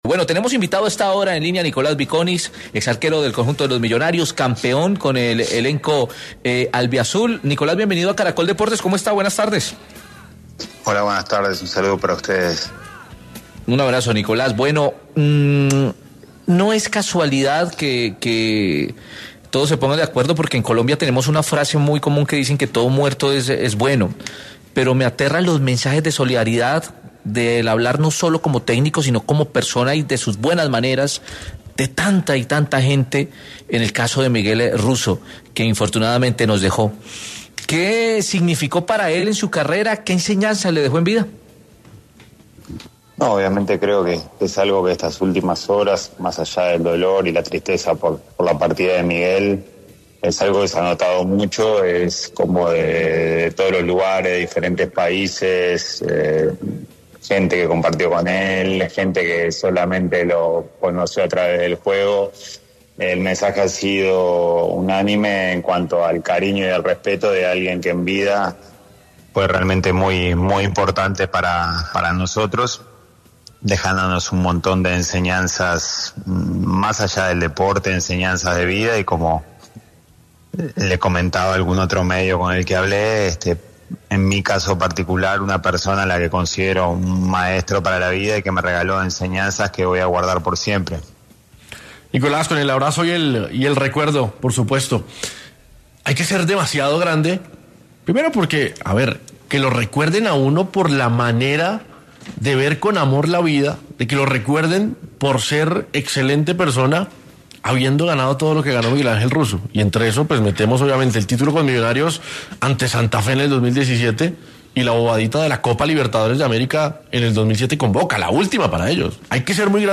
En diálogo con Caracol Deportes, Vikonis recordó a Russo tras su fallecimiento, contando algunas anécdotas de su vida, recordando cómo era como director técnico y las enseñanzas que le dejó para su vida, entre otras cosas.